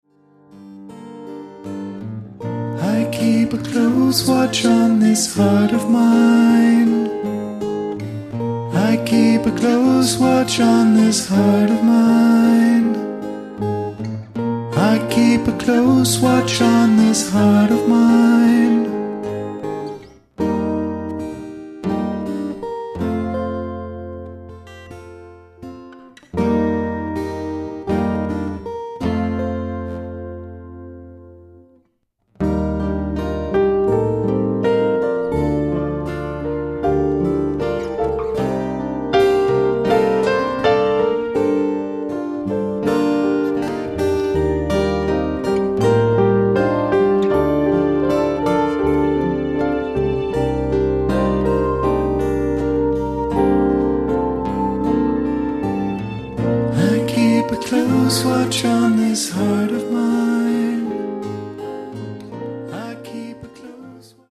acoustic guitar, vox
acoustic guitar, piano, backing vox